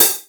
• Clean Urban Open Hat A# Key 14.wav
Royality free open hat one shot tuned to the A# note.
clean-urban-open-hat-a-sharp-key-14-R1s.wav